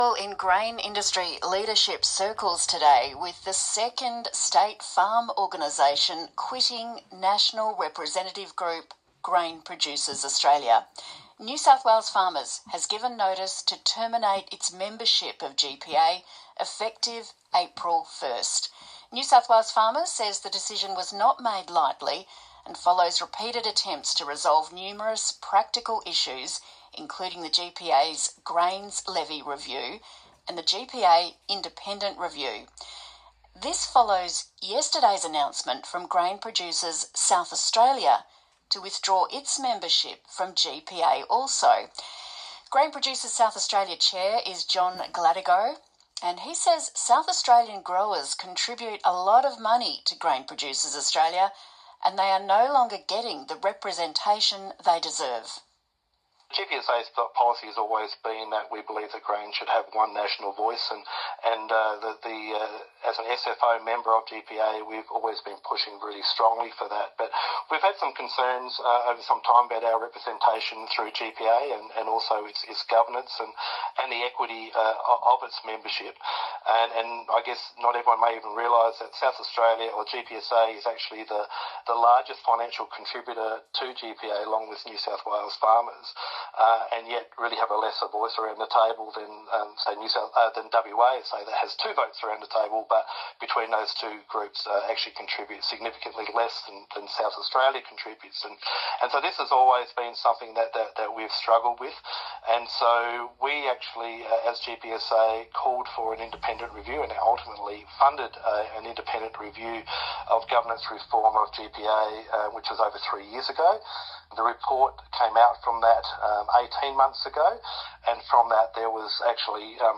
ABC WA COUNTRY HOUR | GPA focused on growers amid member exits